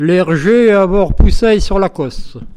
Localisation Île-d'Olonne (L')
Catégorie Locution